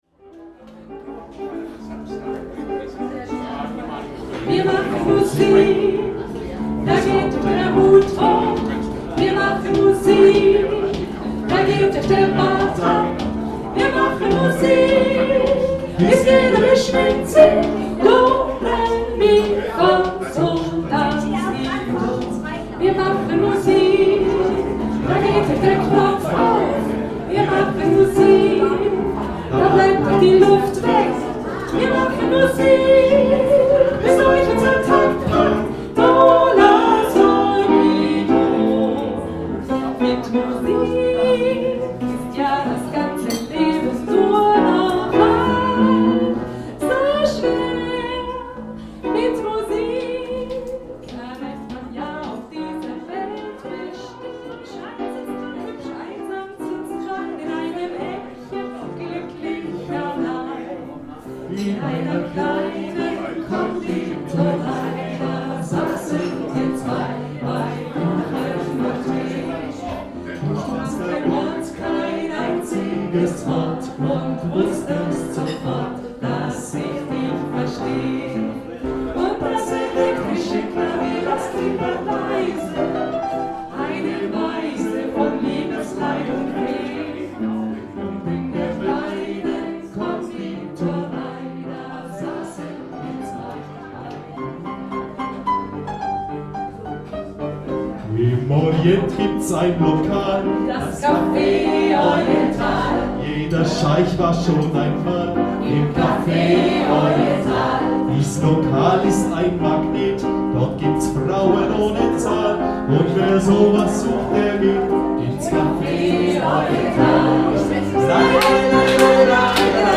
Einjähriges Bestehen des Canapé Café
Schlager und Hits rund um Kanapee, Café und Musik